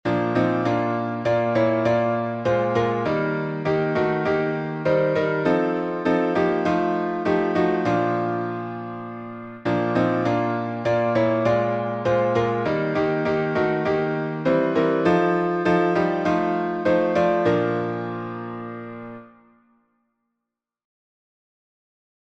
Words and music: traditional Key signature: B flat major (2 flats) Time signature: 4/4 Meter: 12